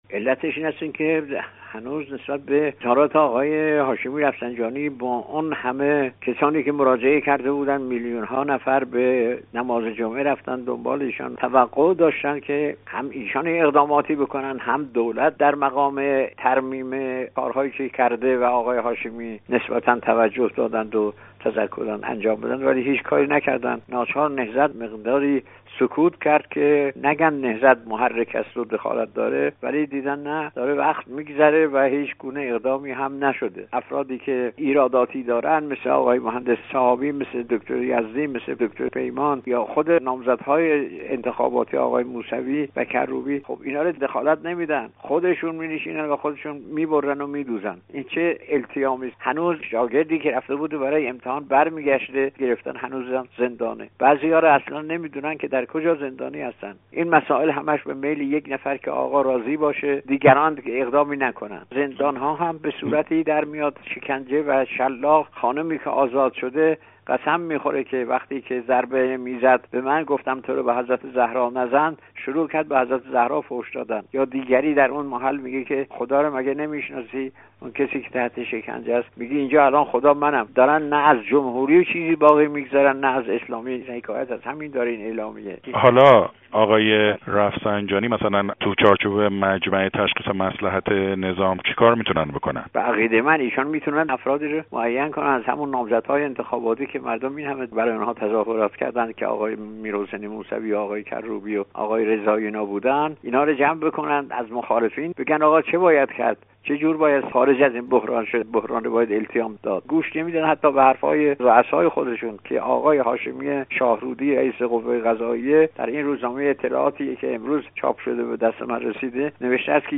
گفت و گو با احمد صدر حاج سید جوادی درباره نامه سرگشاده نهضت آزادی ایران به اکبرهاشمی رفسنجانی